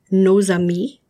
When one word ends in an “n” or “s” and is followed by a word that starts with a vowel sound, you must pronounce the “n” as itself and the “s” as a “z”.
Click on each of the following examples of liaison, and repeat the proper pronunciation after the speaker.